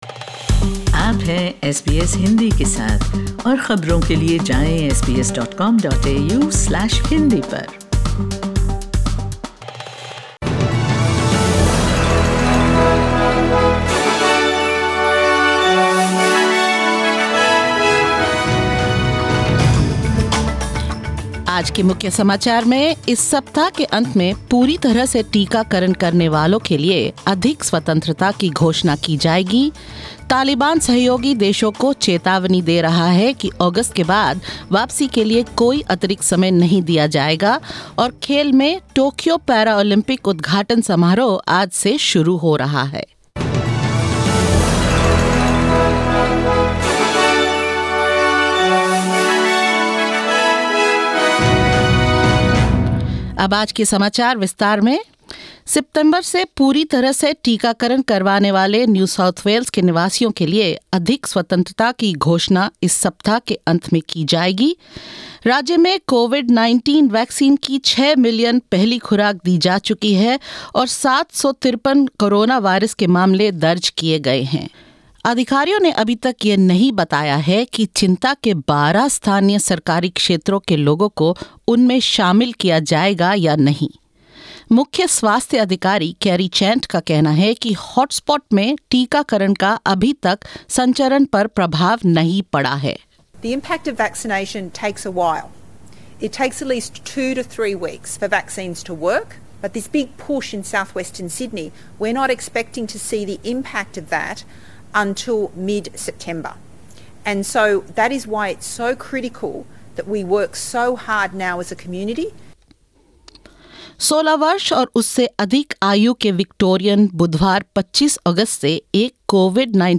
SBS Hindi News 24 August 2021: Vaccinations in NSW have hit the six million target
In this latest SBS Hindi News Bulletin of Australia and India: Greater freedom for those fully vaccinated to be announced later this week; The Taliban is warning allied nations there will be no extension for withdrawal past August; Australia and India to participate in tonight's Paralympic opening ceremony and more.